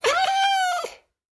豹猫死亡时随机播这些音效
Minecraft_ocelot_death2.mp3